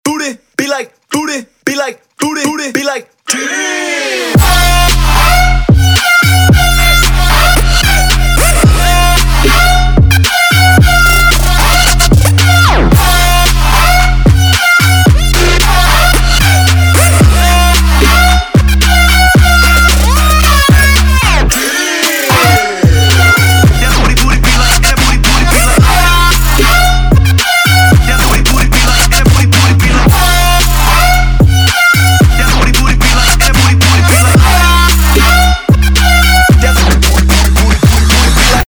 • Качество: 256, Stereo
Trap
Dubstep